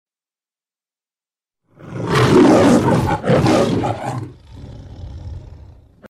ROARING LION.mp3
Original creative-commons licensed sounds for DJ's and music producers, recorded with high quality studio microphones.
roaring_lion_sns.ogg